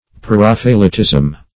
Preraphaelitism \Pre*raph"a*el*i`tism\, n.
preraphaelitism.mp3